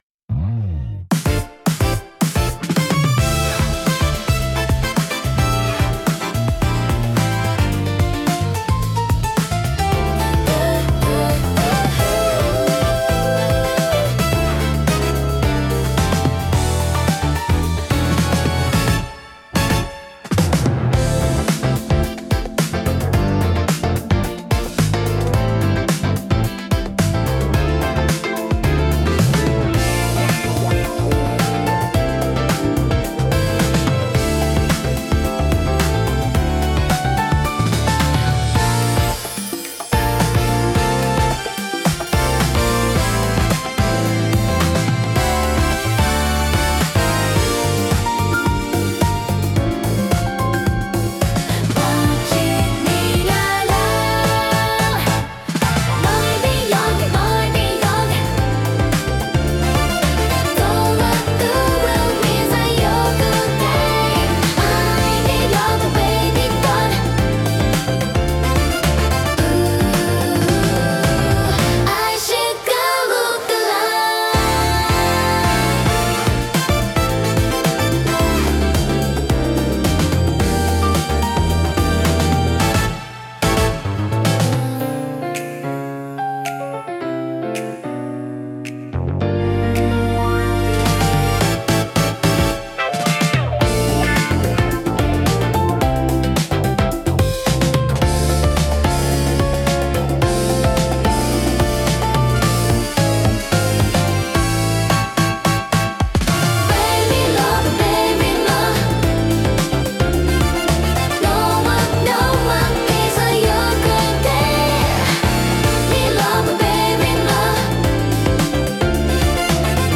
聴く人に洗練された印象を与えつつ、リラックスと活気のバランスを巧みに表現します。